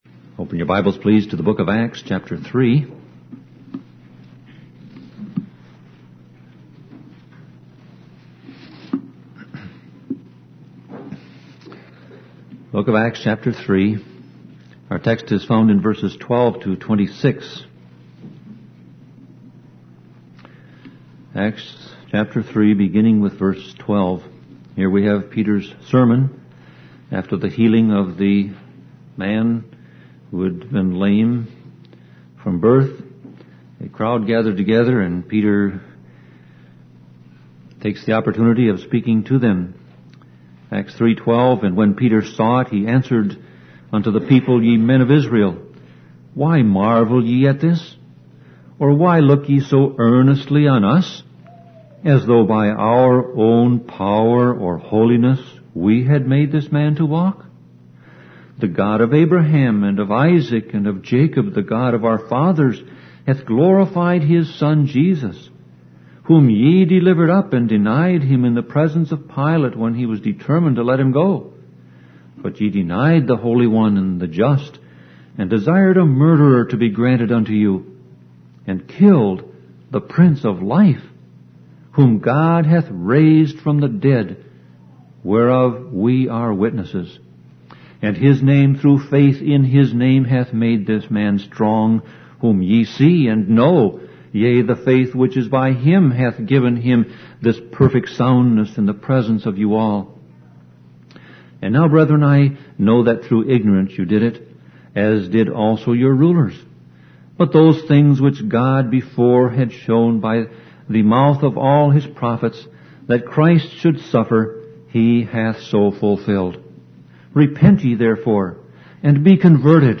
Sermon Audio Passage: Acts 3:12-26 Service Type